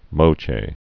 (mōchā, -chĕ) also Mo·chi·ca (mō-chēkə)